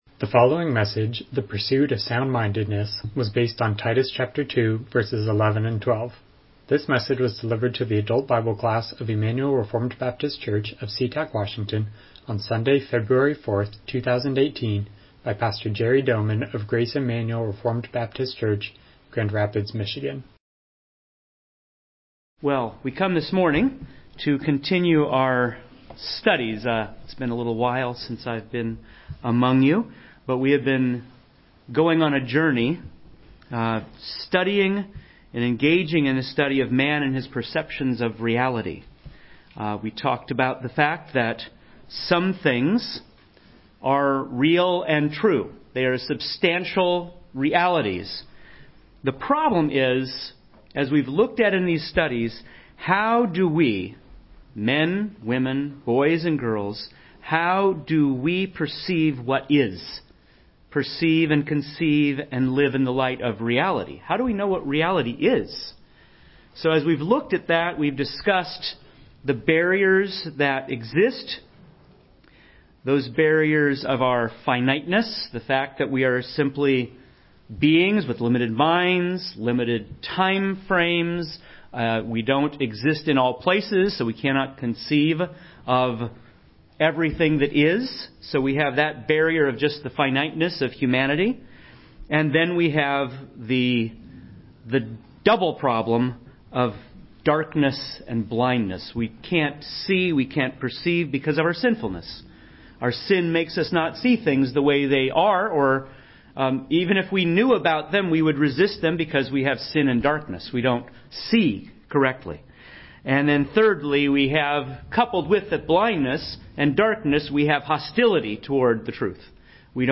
Titus 2:11-12 Service Type: Sunday School « Spiritual Lessons from a Poor